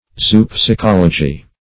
Zoopsychology \Zo`o*psy*chol"o*gy\, n.
zoopsychology.mp3